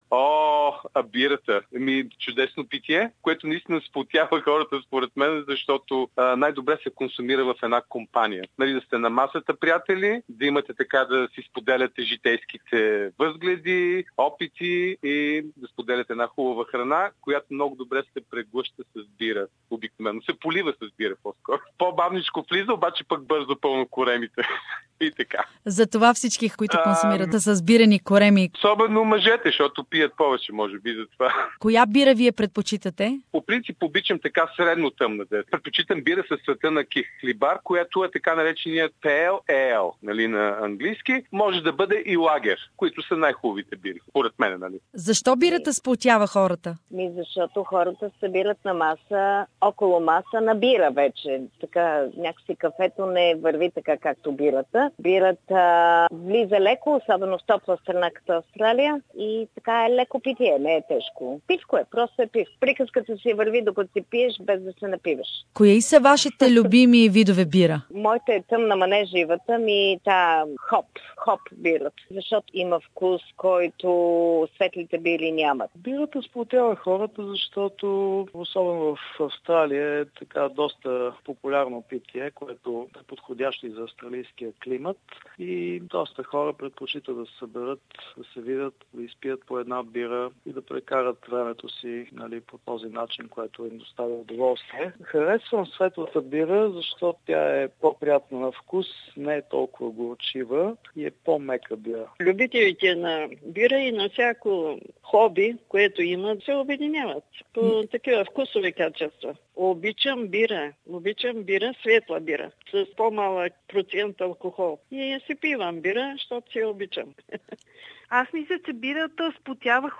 What Bulgarian beer lovers think about the ways beer is uniting people - listen to the pop vox of Bulgarians from Sydney.